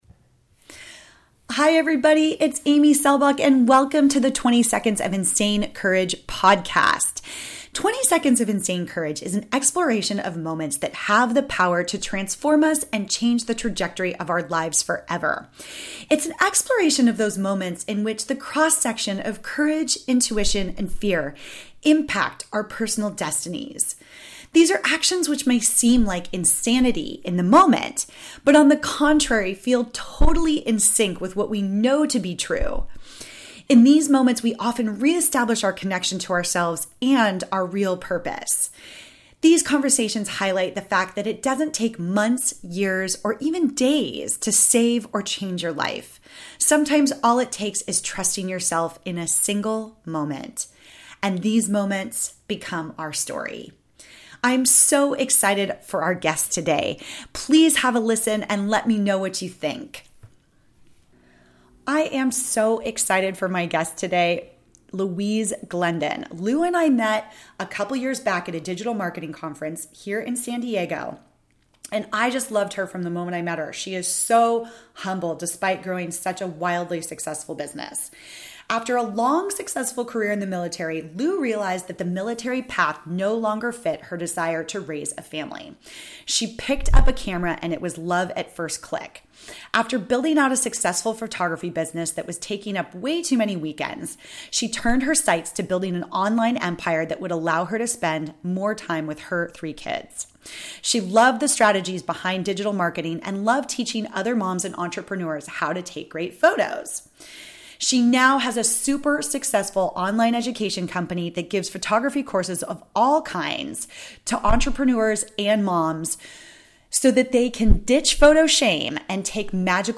One of the things I have been working on is my podcast interview series, “ 20 Seconds of Insane Courage.” 20 Seconds of insane courage is an exploration of moments that have the power to transform us and change the trajectory of our lives forever.